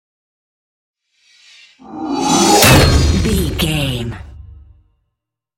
Dramatic horror metal hit large
Sound Effects
Atonal
heavy
intense
dark
aggressive